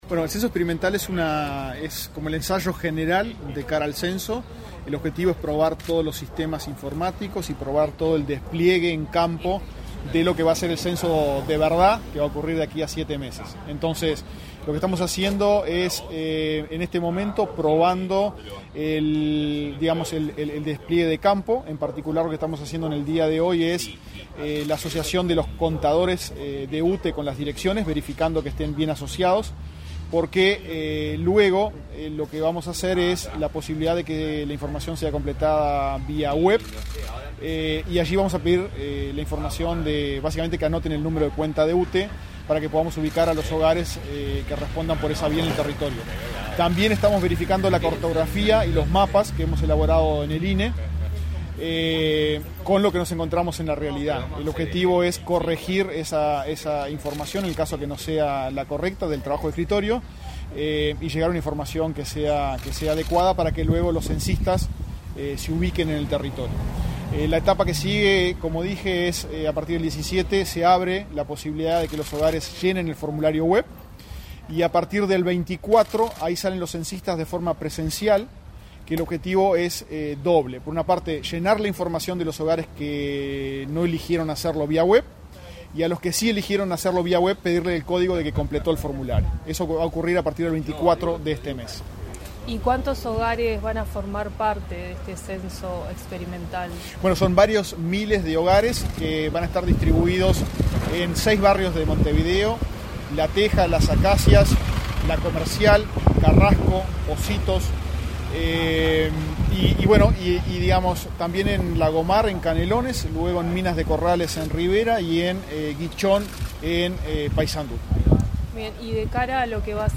Entrevista al director del INE, Diego Aboal